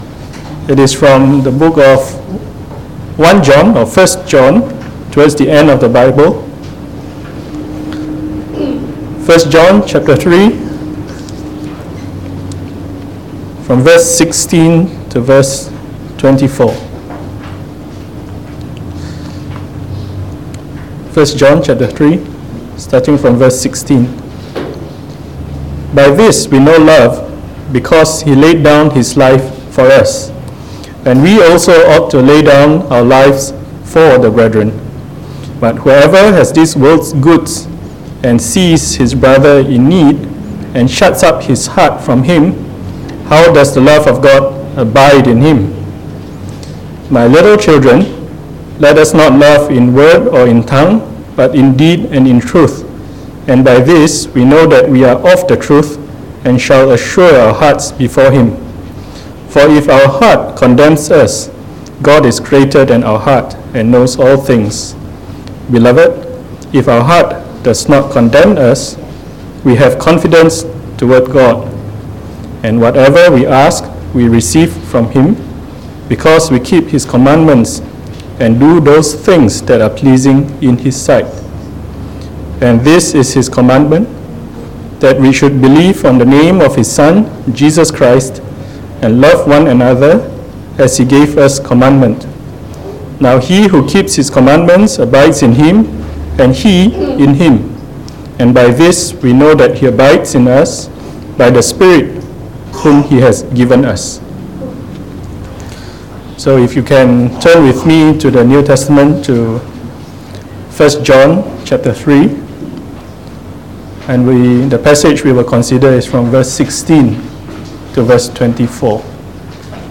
From our series on the Epistle of 1 John delivered in the Evening Service